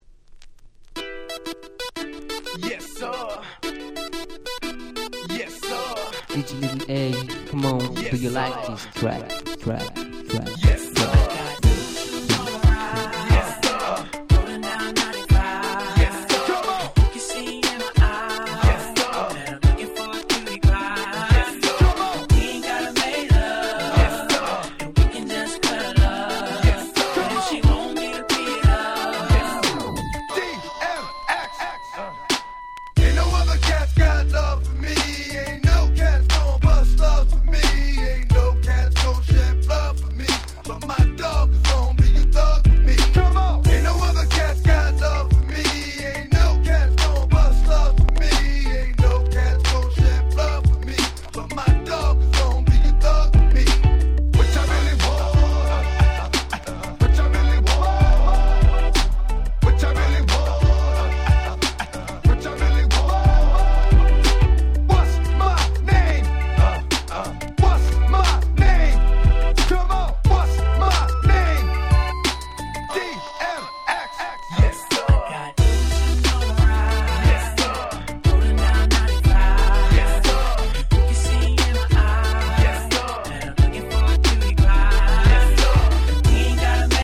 Main Streamヒットを更にフロア仕様にこの盤オンリーとなるRemixを施した使えるシリーズ！！
全6曲全部アゲアゲ！！
勝手にRemix 勝手にリミックス Hip Hop R&B 00's